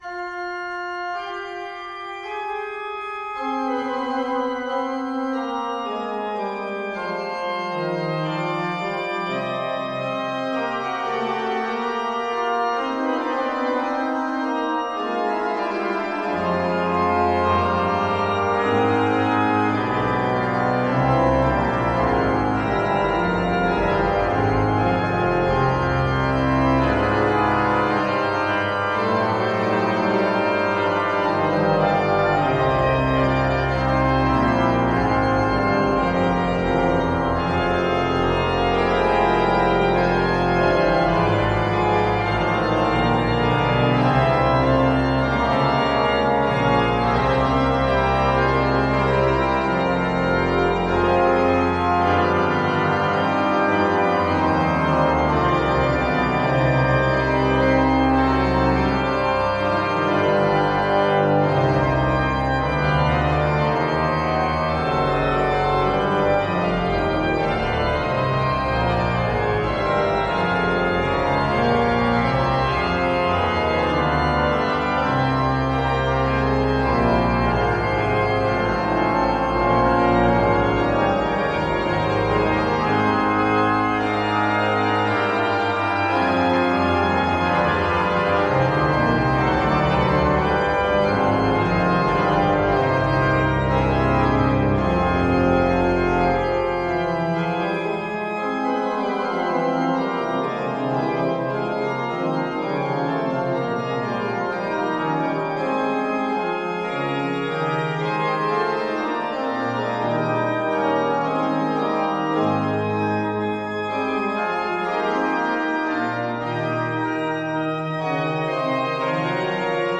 Leonhardskirche (Basel)
CONCERTO D’ORGANO SOLISTA
Andreas Silbermann (1718) - Johann Andreas Silbermann (1771) - Th. Kuhn AG (1969)